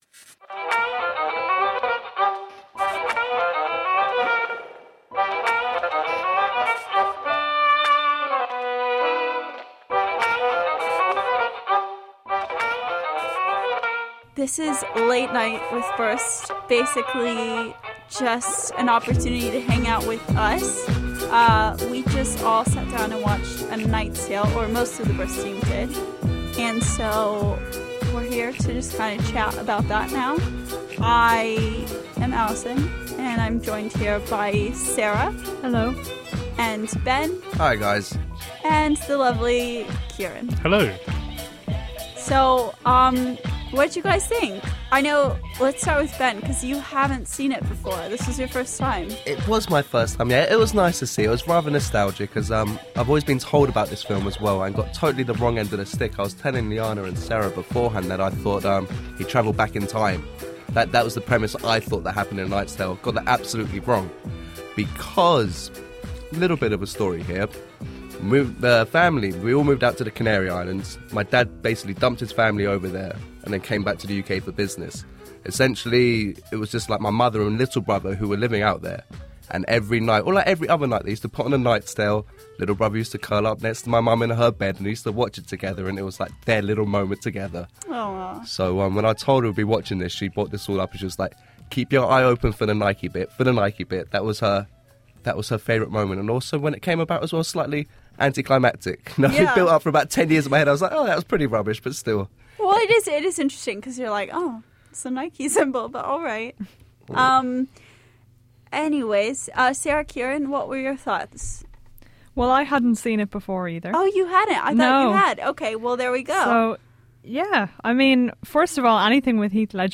Well, here is the conversation we had after watching “A Knight’s Tale.